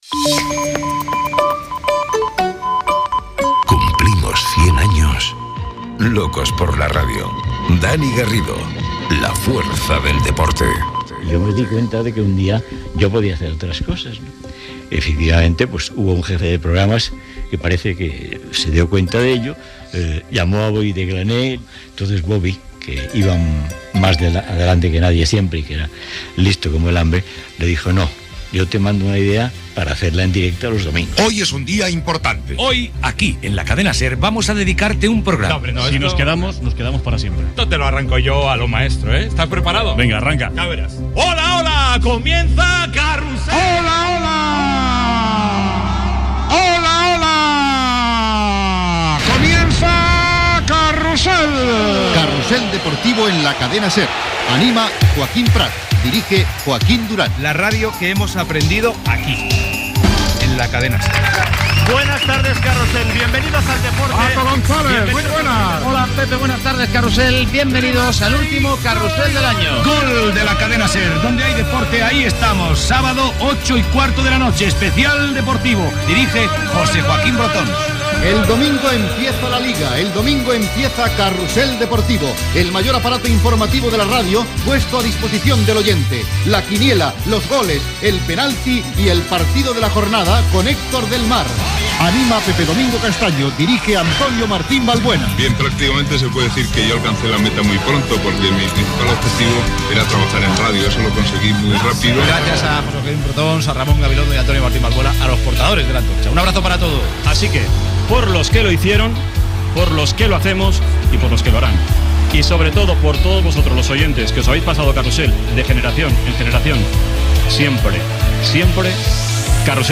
Careta amb un resum sonor d'inicis i fragments de "Carrusel Deportivo" de diverses etapes.